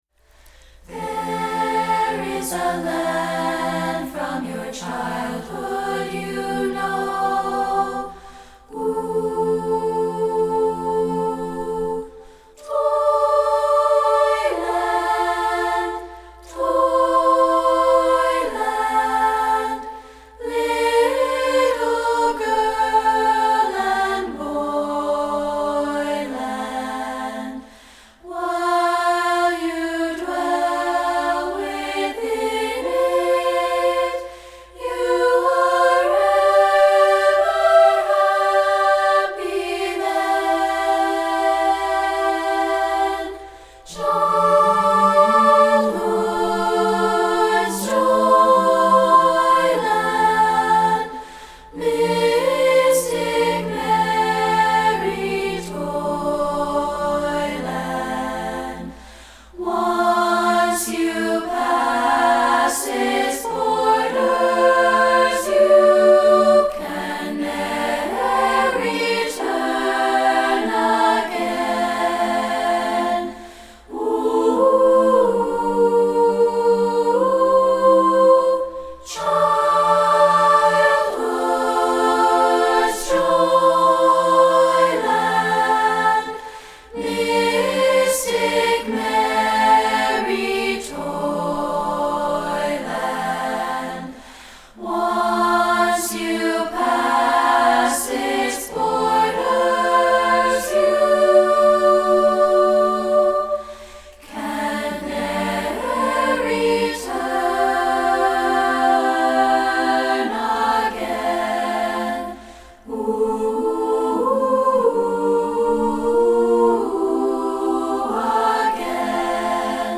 Here's an a cappella track.